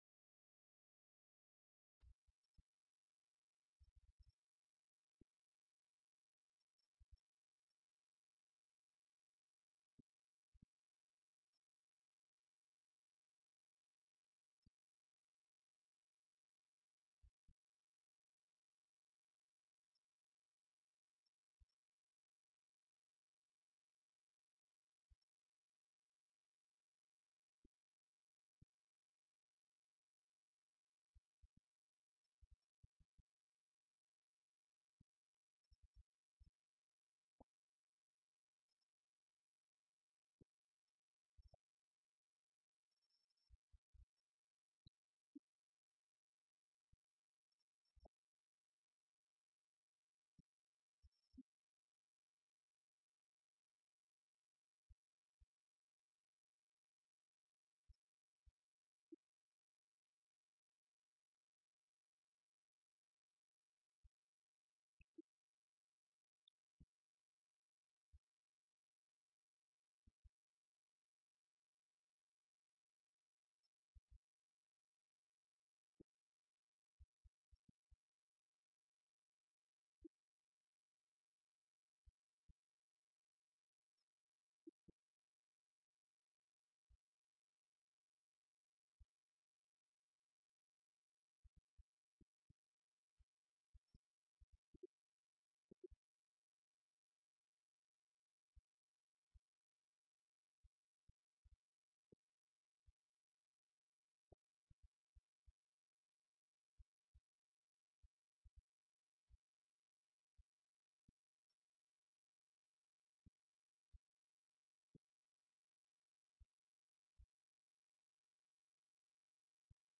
تاريخ النشر ٢٦ صفر ١٤٣٣ هـ المكان: المسجد النبوي الشيخ: فضيلة الشيخ د. عبدالمحسن بن محمد القاسم فضيلة الشيخ د. عبدالمحسن بن محمد القاسم اهتمام الإسلام بطهارة القلب والبدن